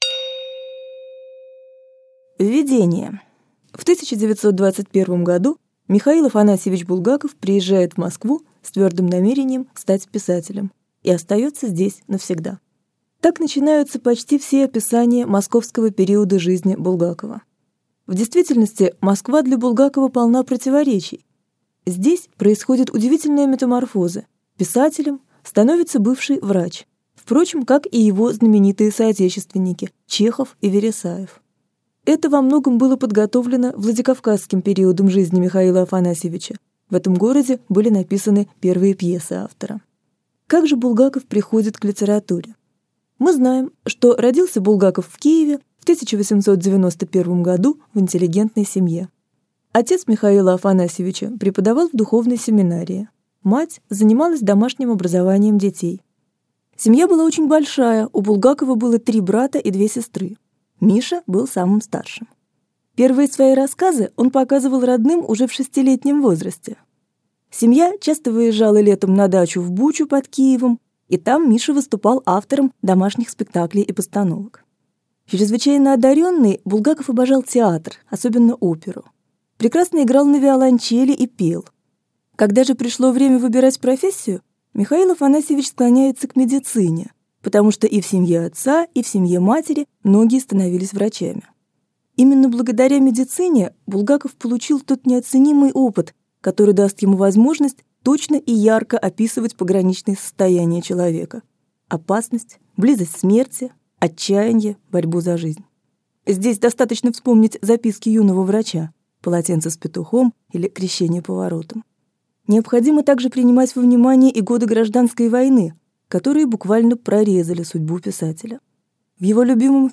Аудиокнига Булгаковская Москва | Библиотека аудиокниг